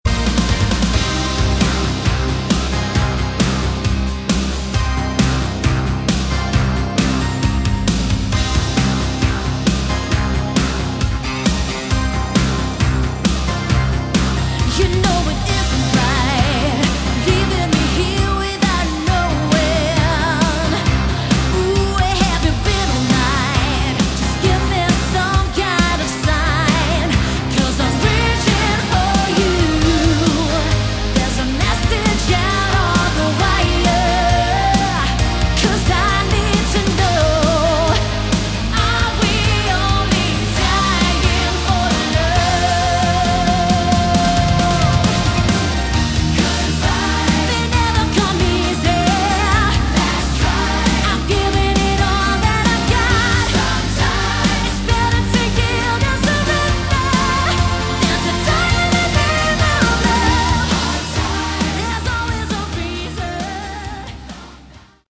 Category: 80s Hard Rock